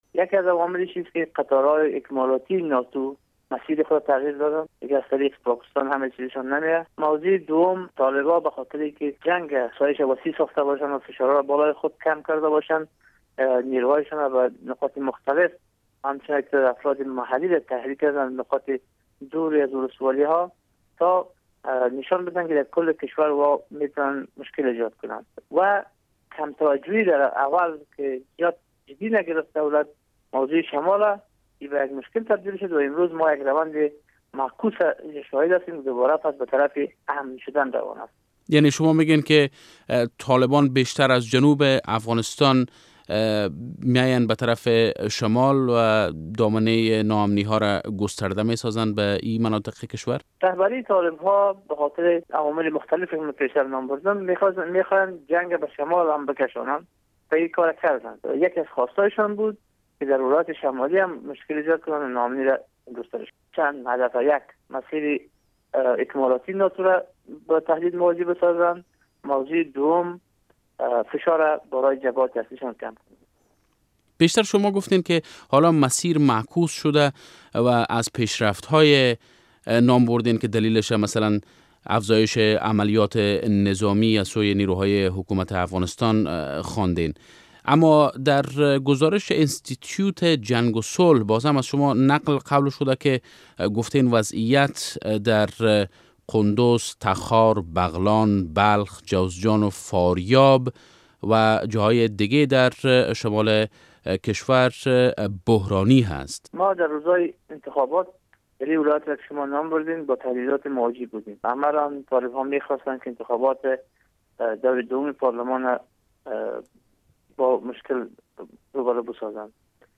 مصاحبه با جنرال داوود داوود در مورد دلایل اصلی نفوذ طالبان به شمال افغانستان